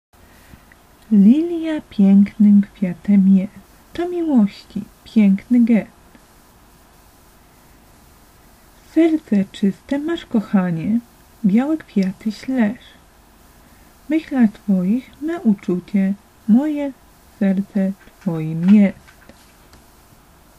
Audiobook z wierszykiem "Lilja"Słuchaj